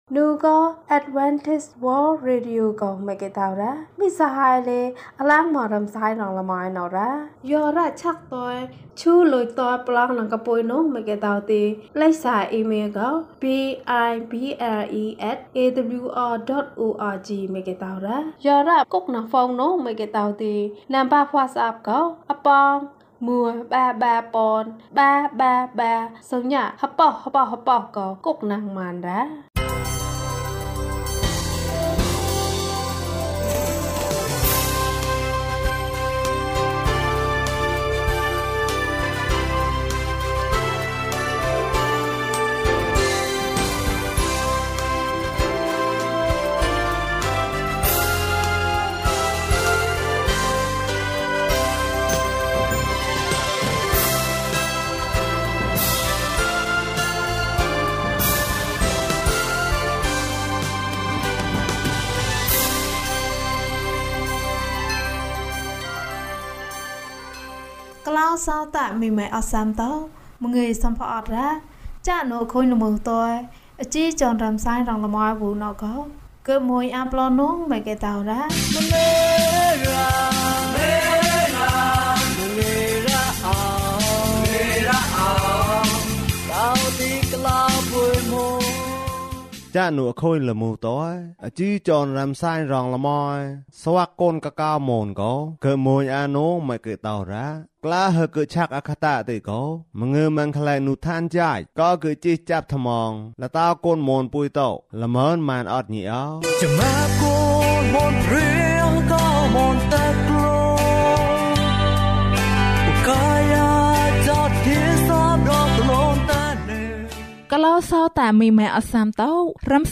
ယေရှုမင်းကိုချစ်တယ် အပိုင်း ၁။ ကျန်းမာခြင်းအကြောင်းအရာ။ ဓမ္မသီချင်း။ တရားဒေသနာ။